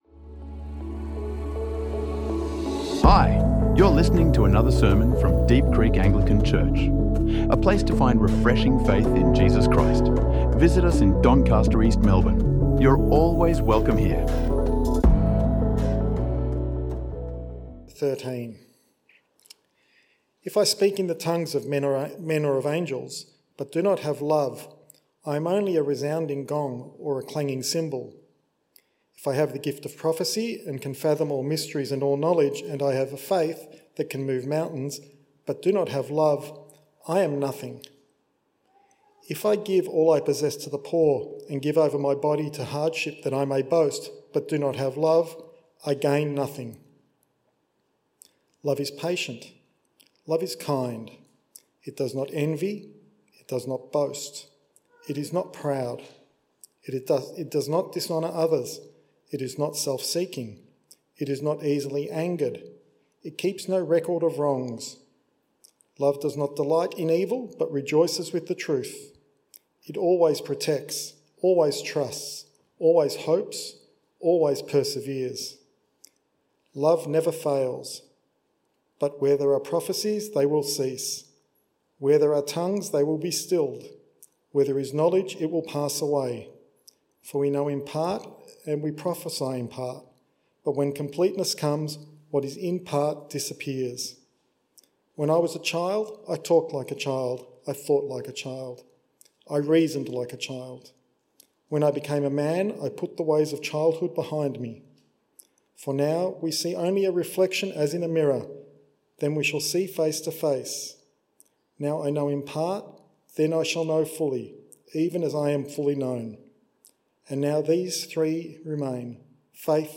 Because of Our Love | Sermons | Deep Creek Anglican Church